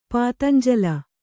♪ pātanjala